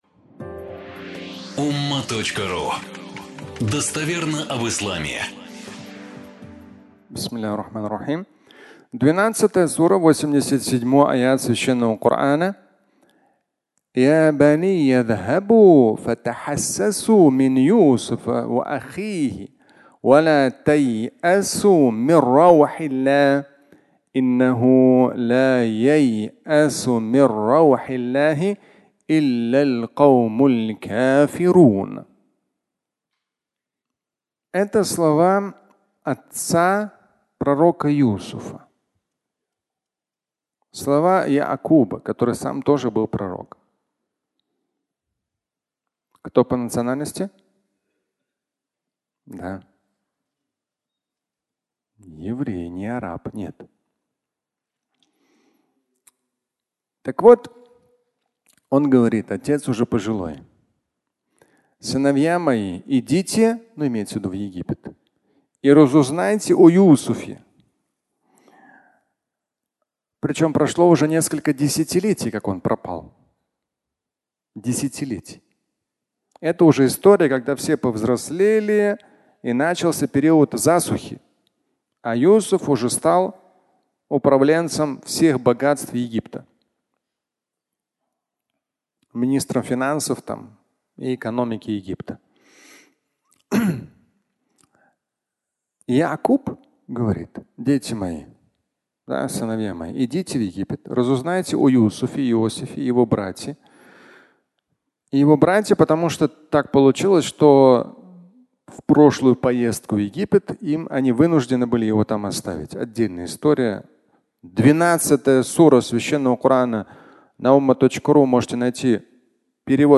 Клещи судьбы (аудиолекция)